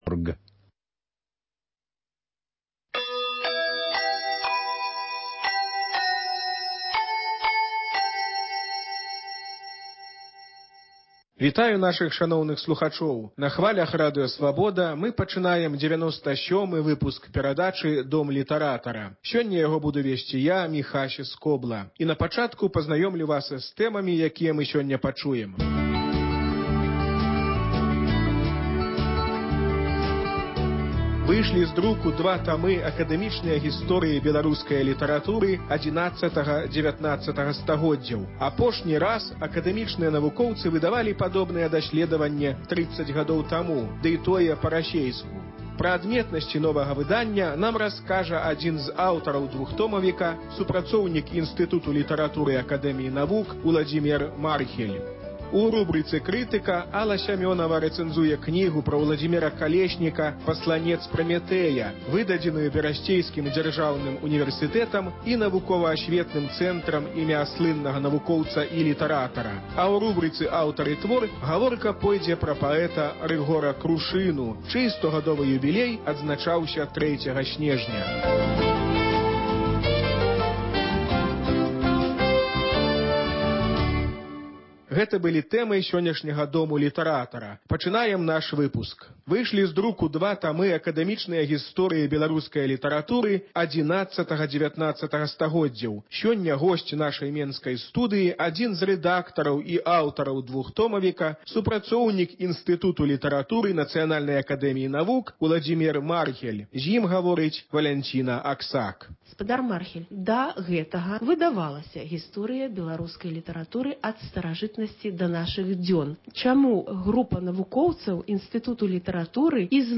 Літаратурны агляд